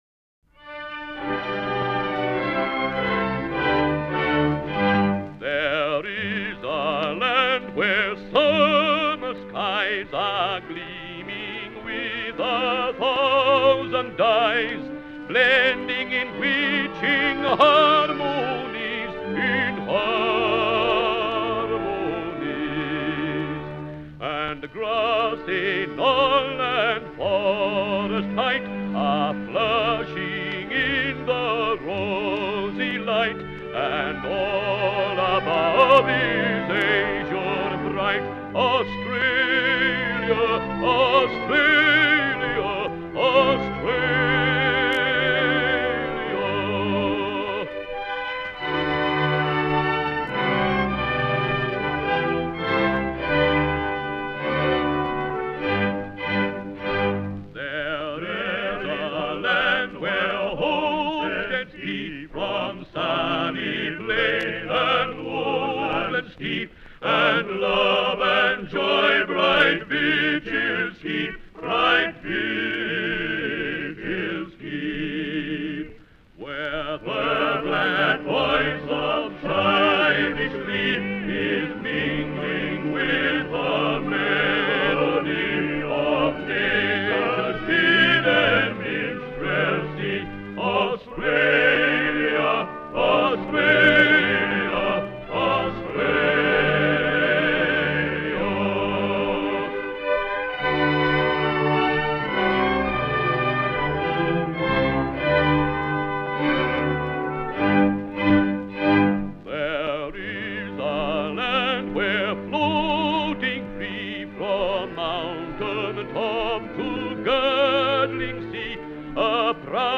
Music by Carl Linger and words by Caroline Carleton, conducted by Lawrance Collingwood, recorded by His Master’s Voice HMV EA 1093, February 1932